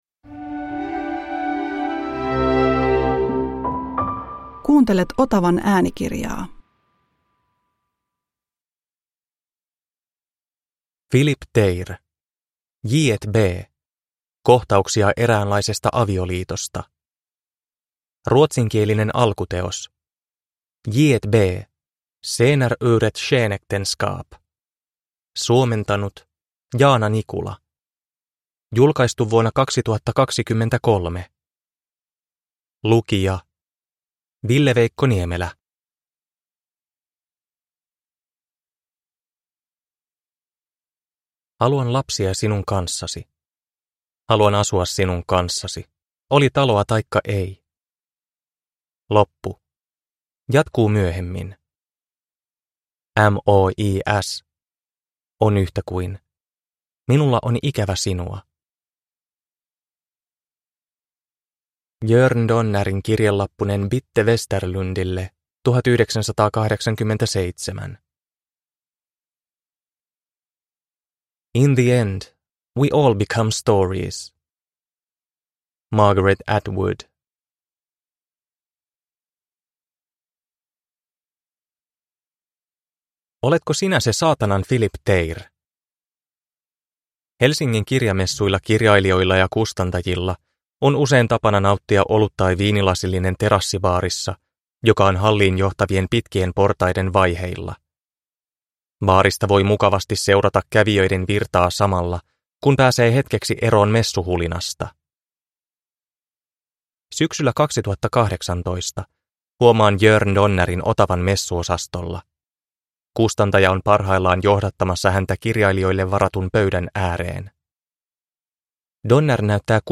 J&B – Ljudbok – Laddas ner